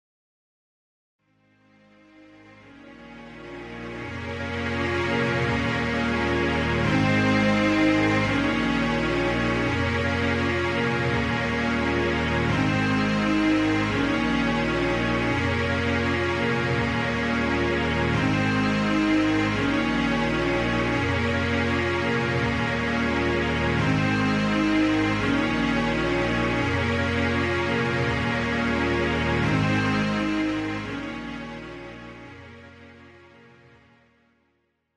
This is an instrumental backing track cover.
• Key – A♭
• With Backing Vocals
• With Fade